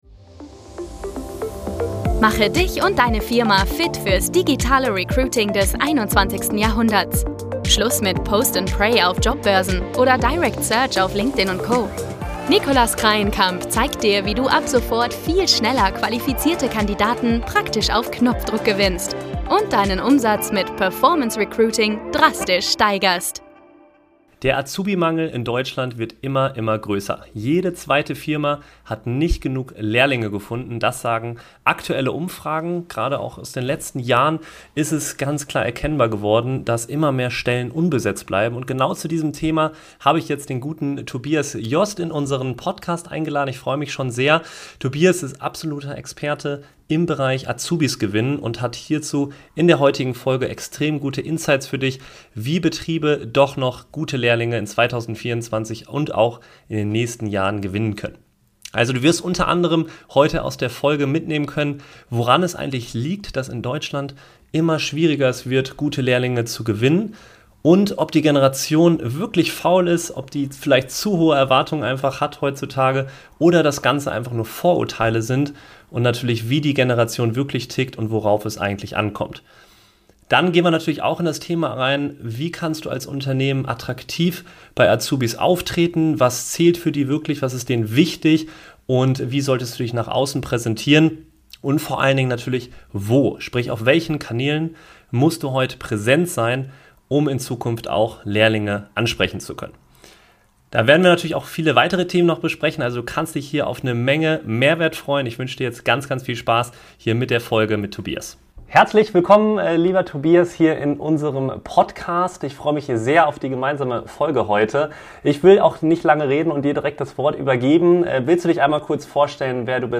In diesem Live-Interview